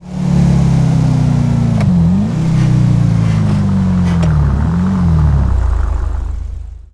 Index of /server/sound/vehicles/tdmcars/gtav/mesa3
throttle_off.wav